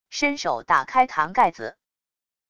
伸手打开坛盖子wav音频